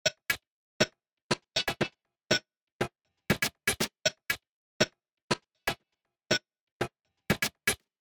A continuación puedes ver cómo se activan los golpes de platillos no tonales.
Audio con puerta y sin reverb: